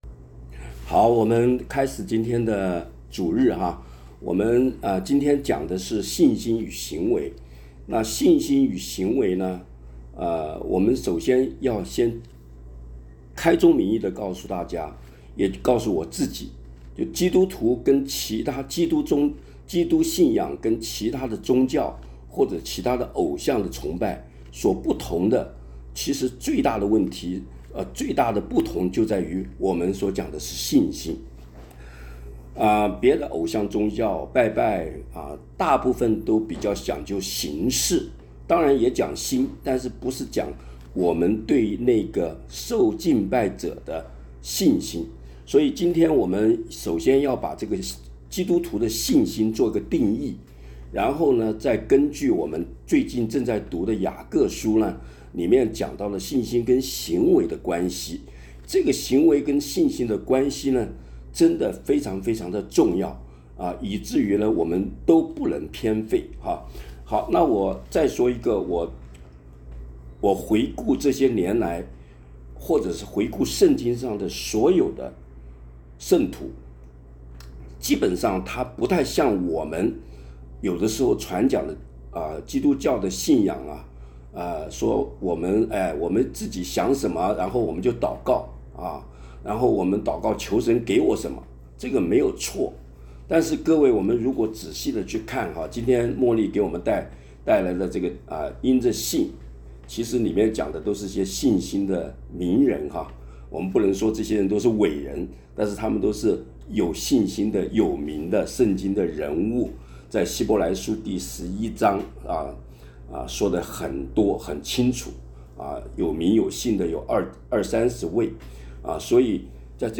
感谢主又让我们在空中团聚。 本月主题 《亚伦的祝福》 。